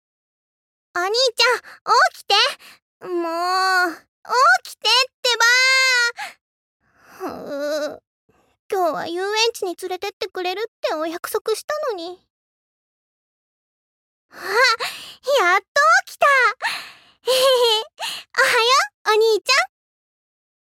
ボイスサンプル
元気な妹